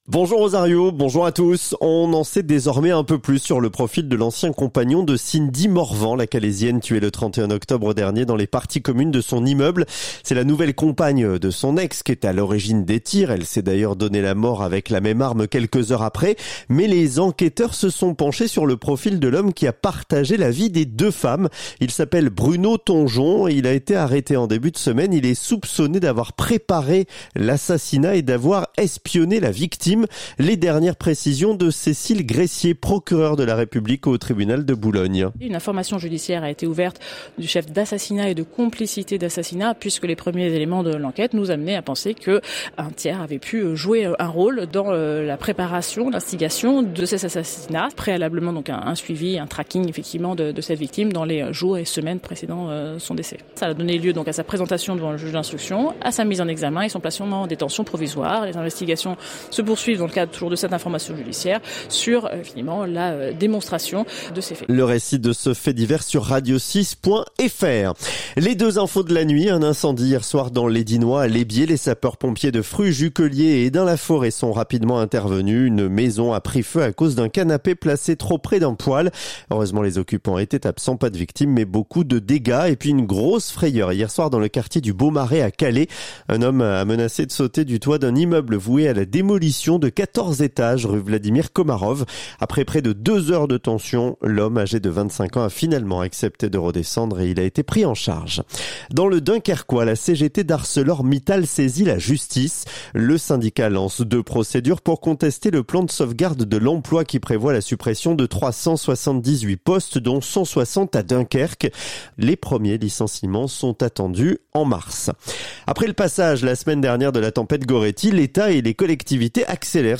Le journal du samedi 17 janvier